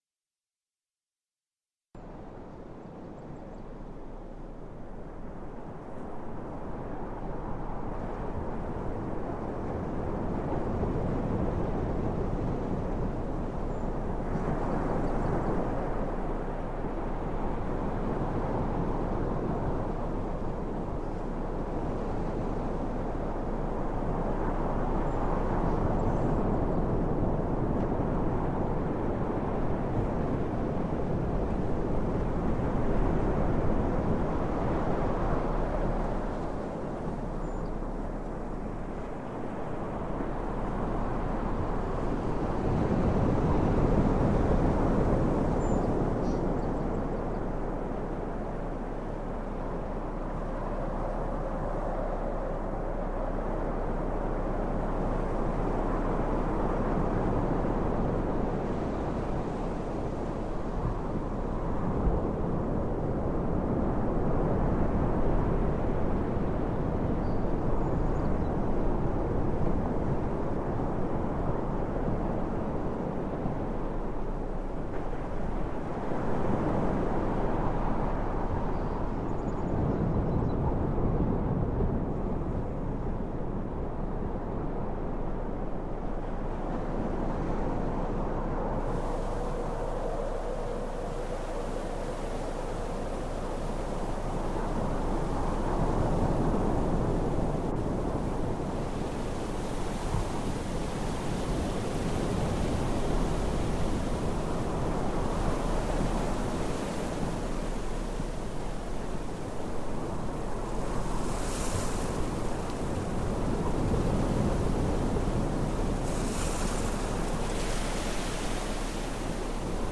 Bilateral ocean sounds